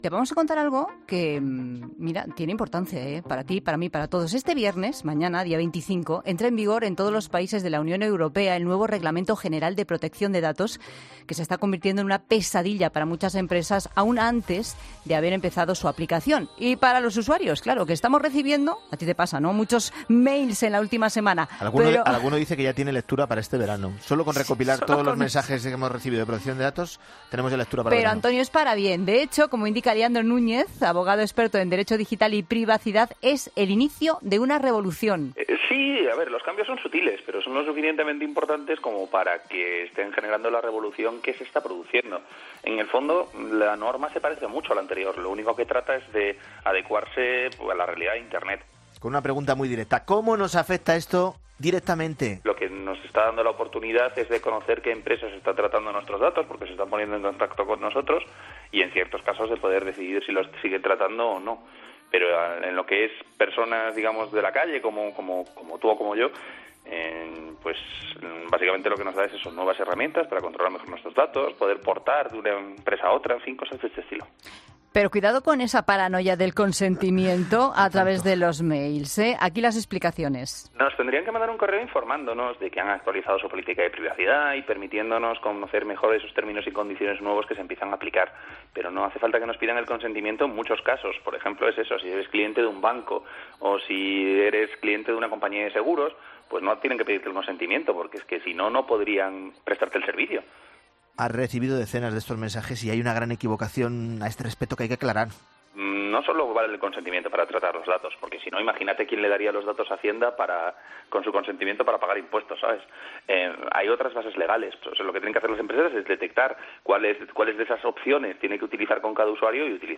abogado experto en privacidad y nuevas tecnologías ante el RGPD